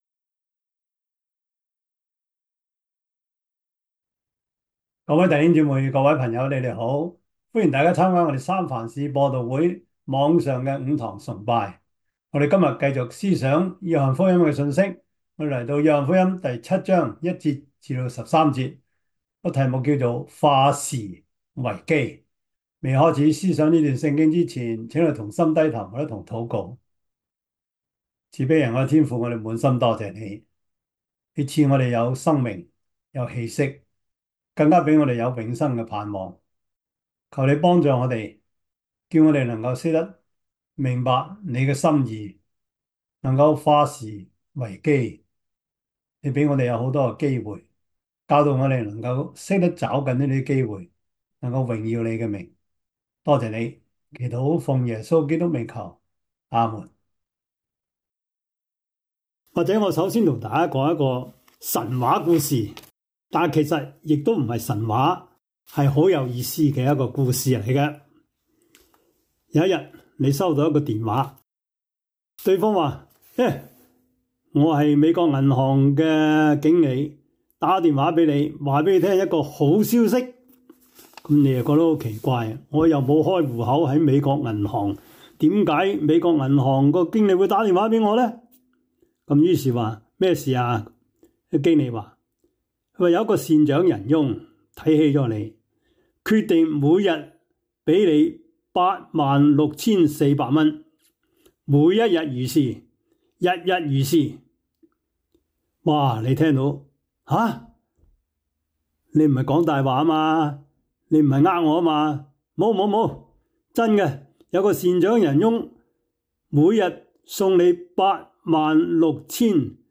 約翰福音 7:1-13 Service Type: 主日崇拜 約翰福音 7:1-13 Chinese Union Version